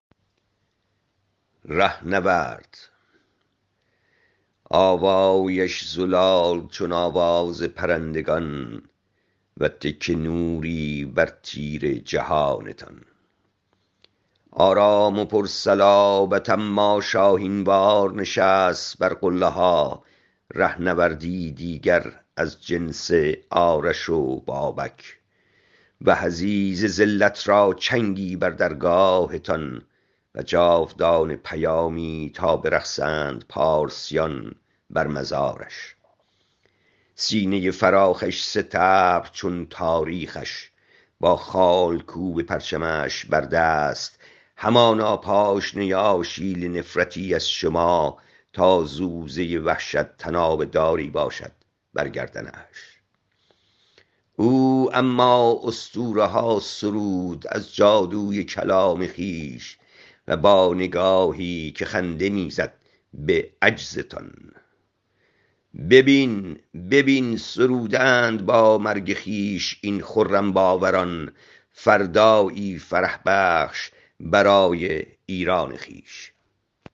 این شعر را می توانید با صدای شاعر بشنوید